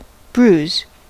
Ääntäminen
US : IPA : [bruːz]